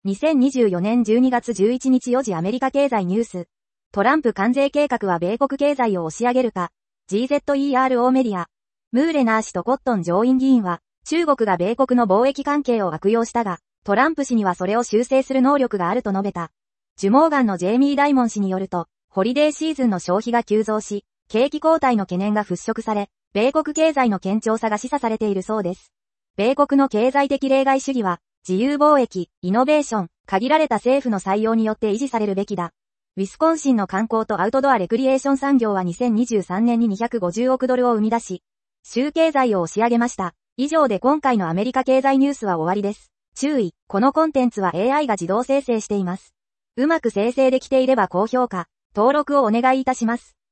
海外ニュースを仕入れてお届け。 - 2024年12月11日04時 アメリカ経済ニュース